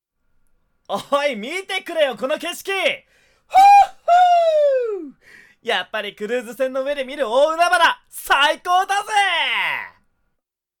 ⑥ 明るい青年
明るい青年.mp3